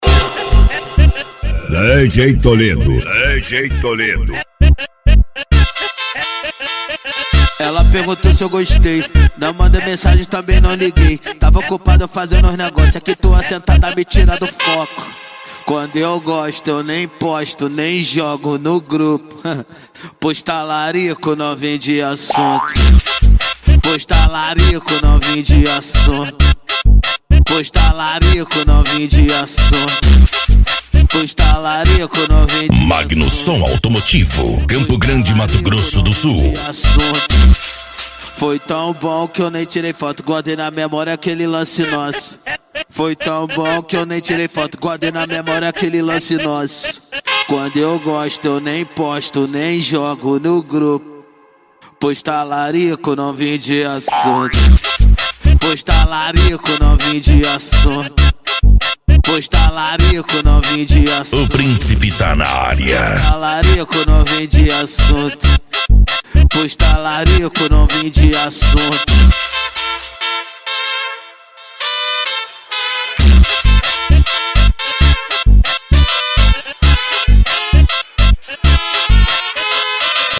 Funk
Sertanejo Universitario